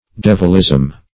Search Result for " devilism" : The Collaborative International Dictionary of English v.0.48: Devilism \Dev"il*ism\, n. The state of the devil or of devils; doctrine of the devil or of devils.
devilism.mp3